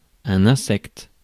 Ääntäminen
Ääntäminen France: IPA: [ɛ̃.sɛkt] Haettu sana löytyi näillä lähdekielillä: ranska Käännös Konteksti Ääninäyte Substantiivit 1. bug puhekieli US 2. insect eläintiede US Suku: m .